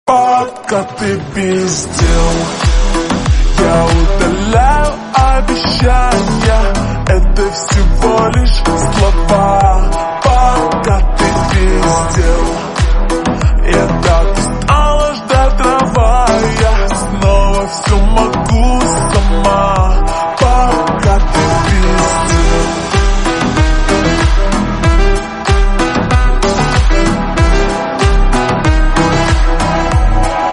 поп
женский голос
спокойные
медленные